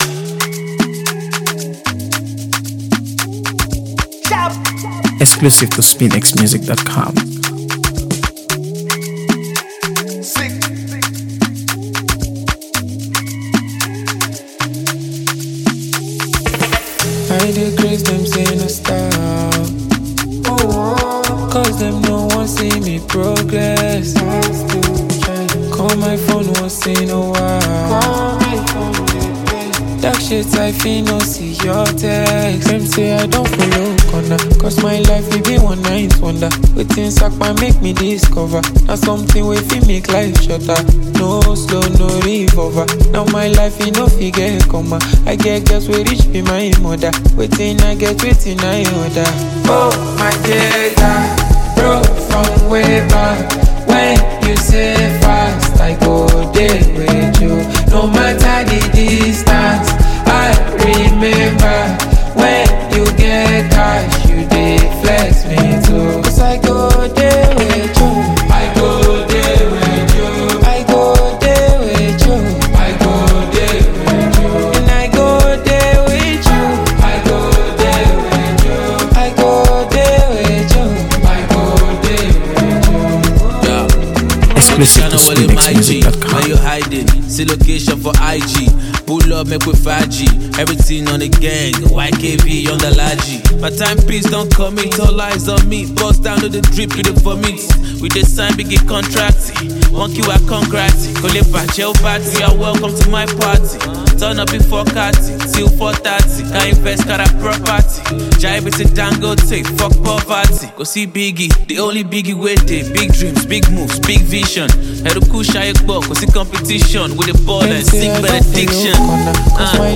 singer-songwriter